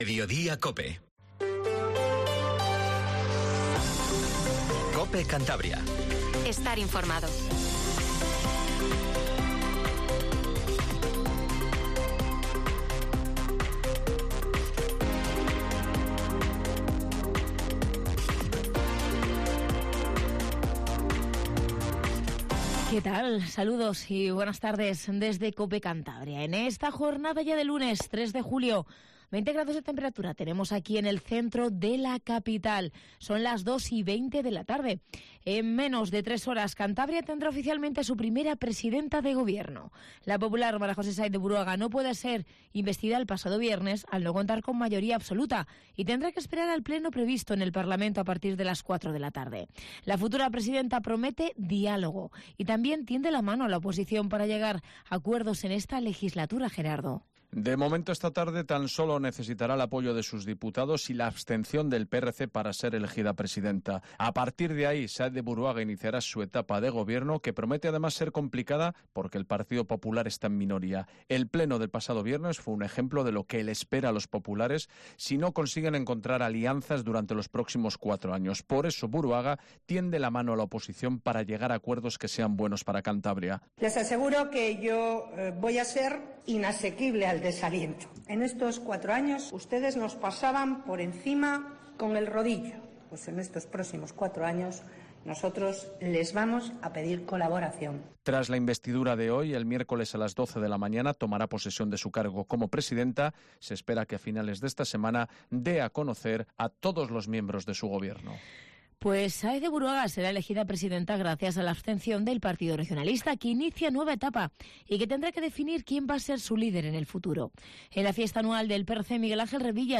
Informativo Regional 1420